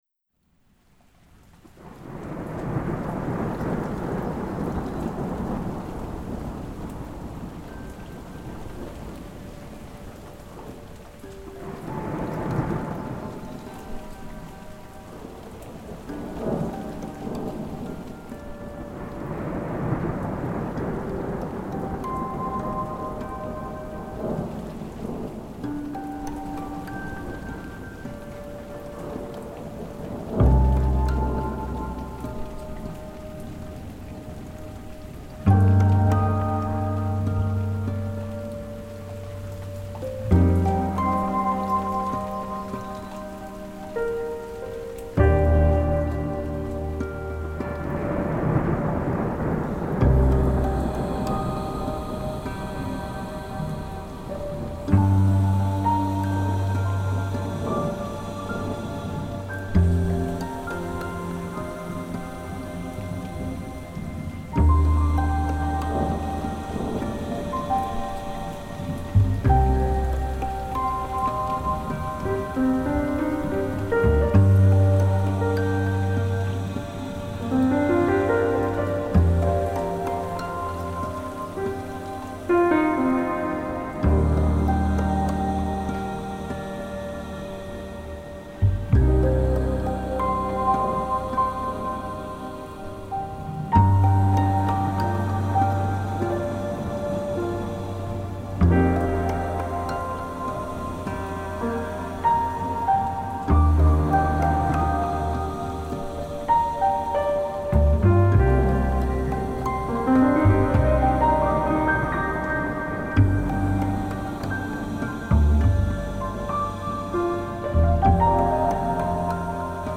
Un disque de jazz orchestral, luxuriant et atmosphérique…